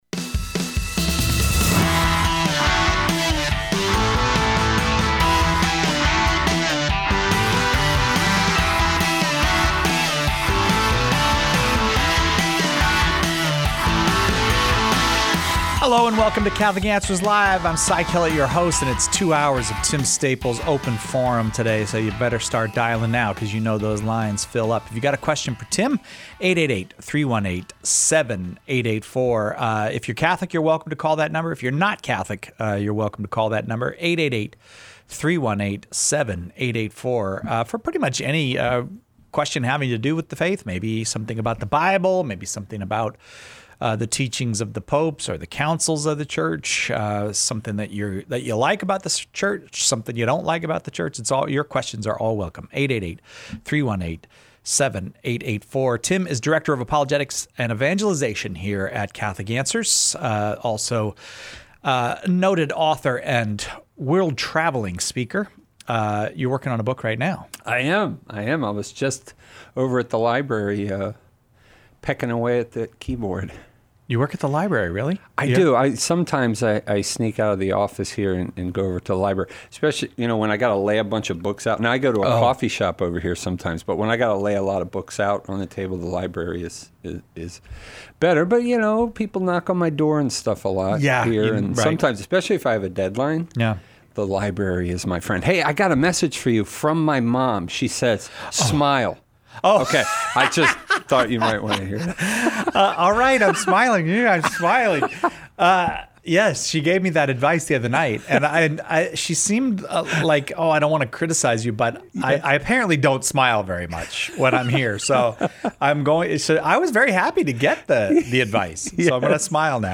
Callers choose the topics during Open Forum!